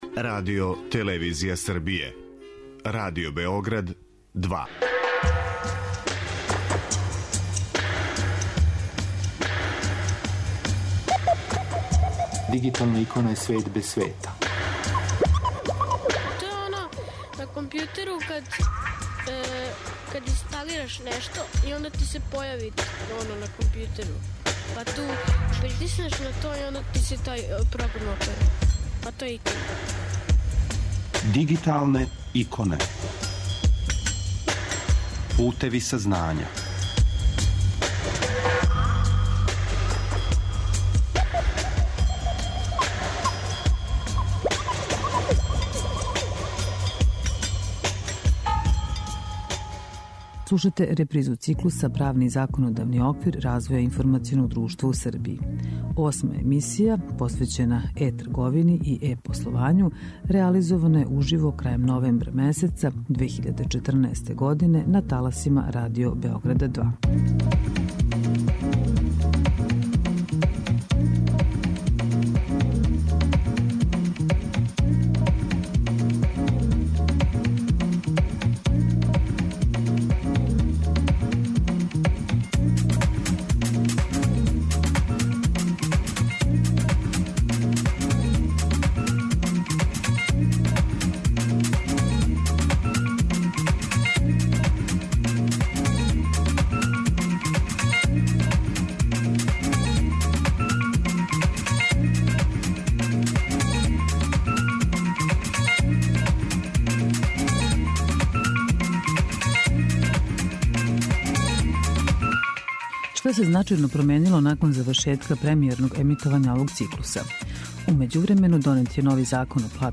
Слушате репризу циклуса 'Правни и законодавни оквир развоја информационог друштва у Србији'. Осма емисија, посвећена е-трговини и е-пословању, реализована је крајем новембра 2014. године, уживо, на таласима Pадио Београда 2.